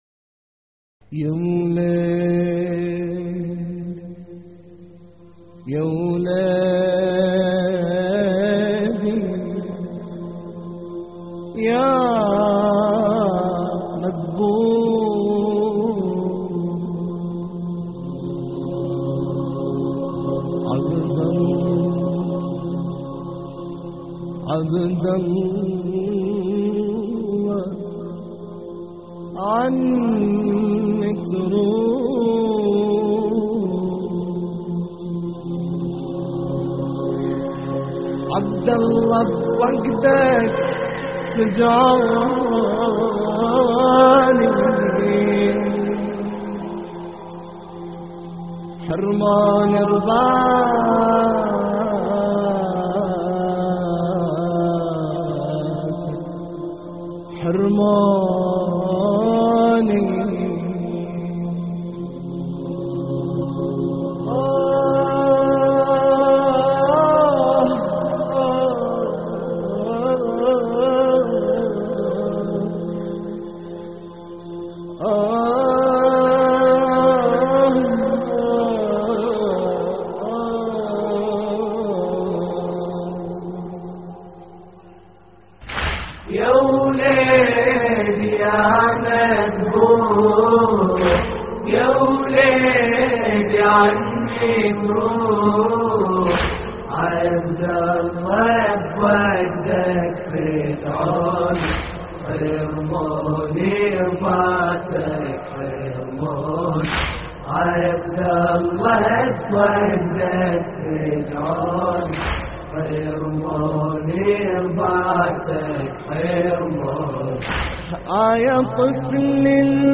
اللطميات الحسينية
موقع يا حسين : اللطميات الحسينية يوليدي يا مذبوح عبد الله - استديو «المسبيات» لحفظ الملف في مجلد خاص اضغط بالزر الأيمن هنا ثم اختر (حفظ الهدف باسم - Save Target As) واختر المكان المناسب